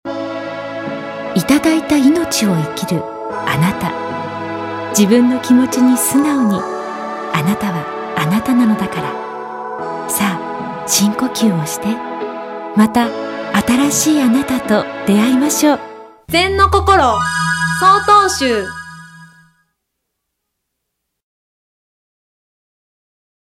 ■ラジオCM「自分らしく」篇（mp3ファイル）